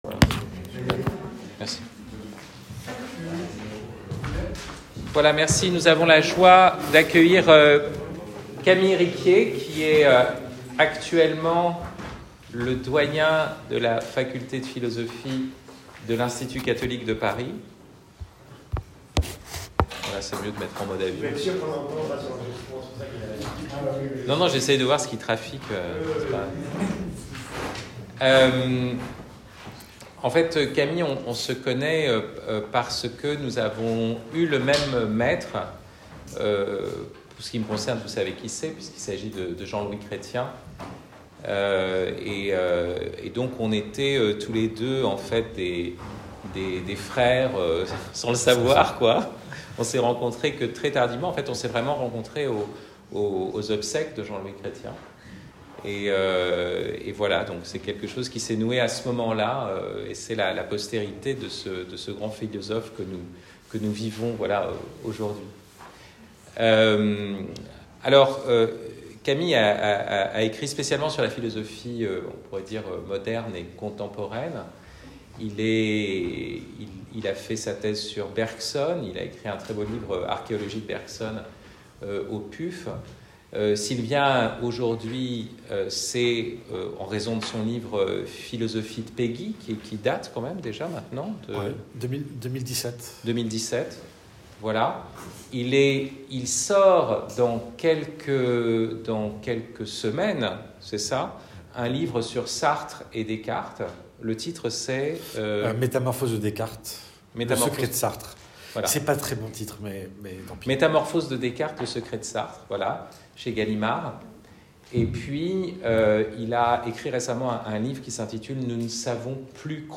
La pensée de Péguy, à la fois conservateur et socialiste, permet de faire bouger les lignes et d’approcher la question politique avec une profondeur qui déjoue les facilités partisanes. Conférence